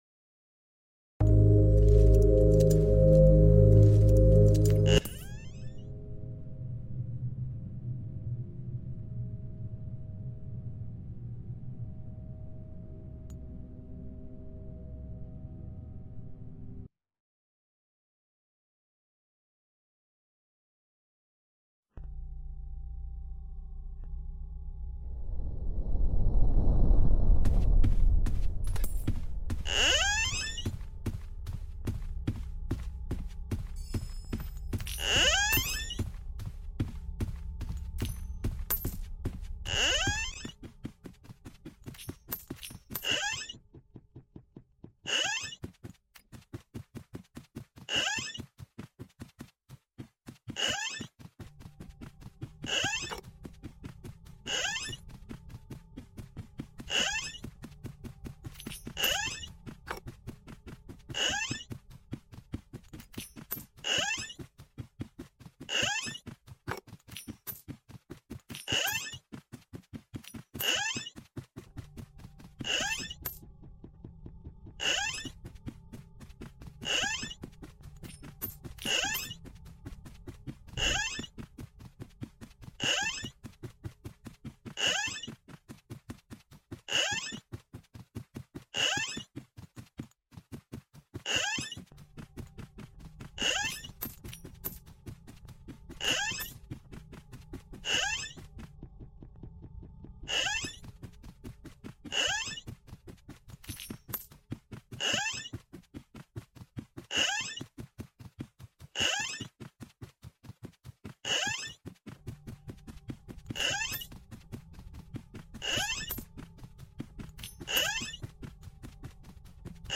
Roblox doors A 000 to A 150 sound effects free download